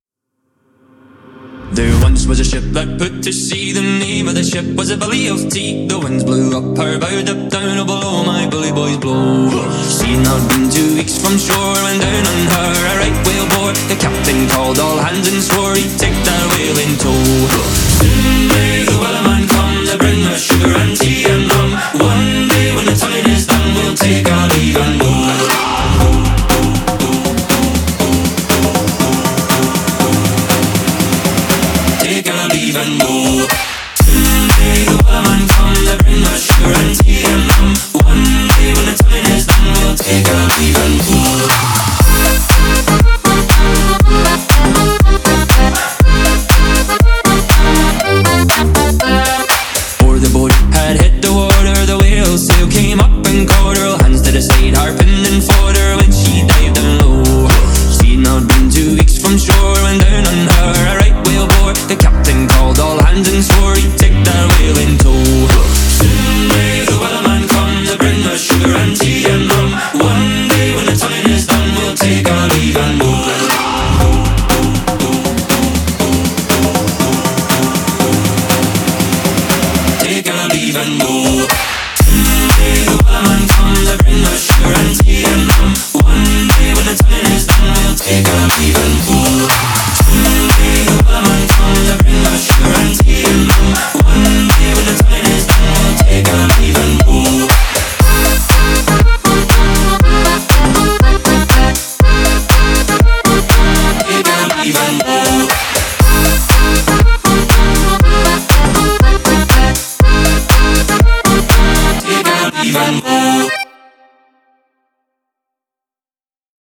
Веселая кантри- песенка)